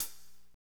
HAT P C C0FL.wav